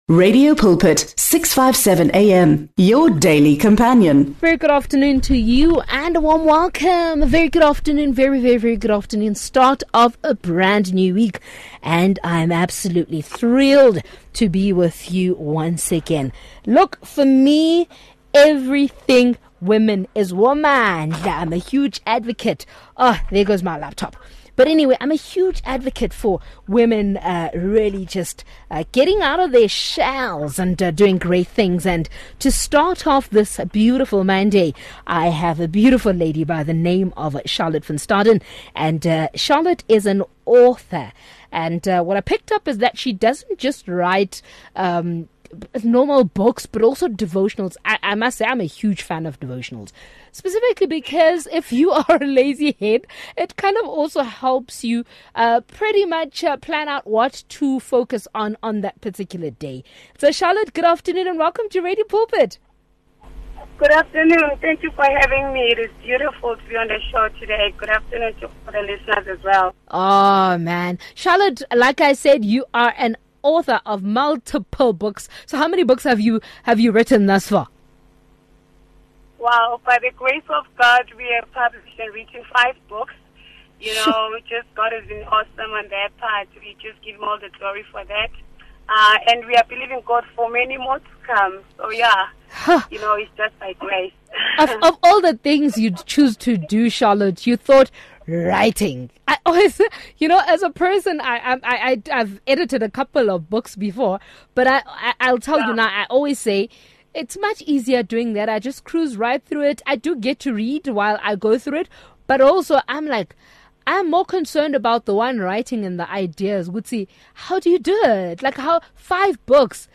5 Feb Book Review